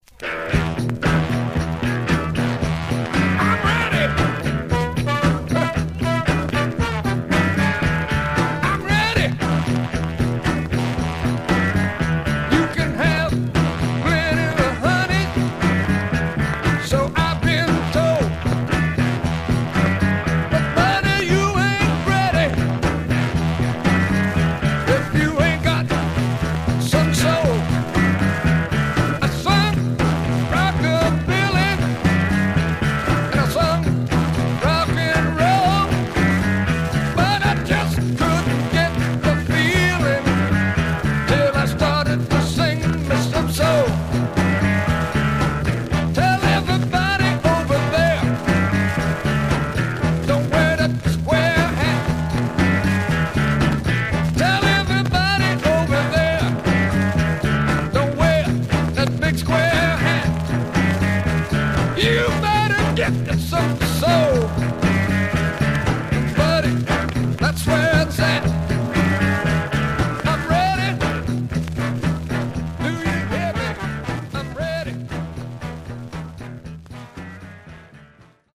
Much surface noise/wear Stereo/mono Mono
Funk